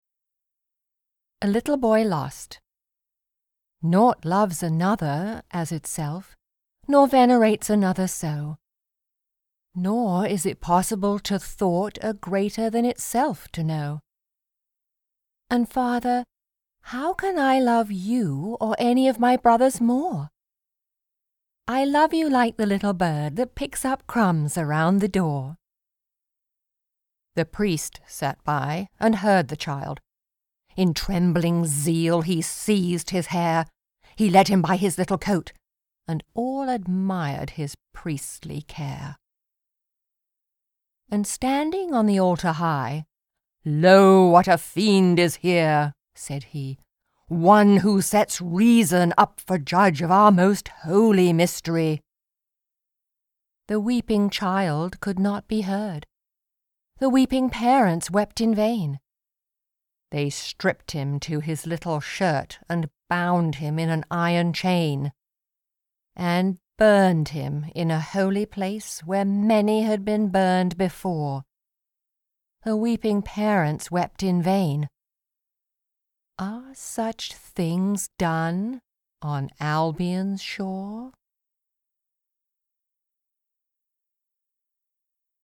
Readings of all the poems from Songs of Innocence and Songs of Experience.